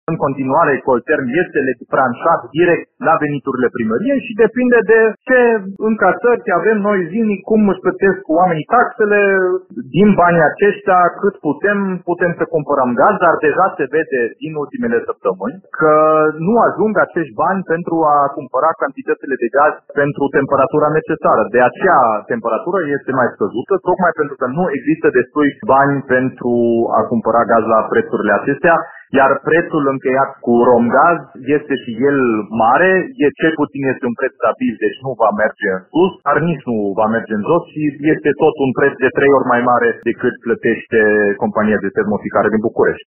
Primarul Dominic Fritz spune că, în tot acest timp, Colterm supraviețuiește de pe o zi pe alta, cu bani de la bugetul local.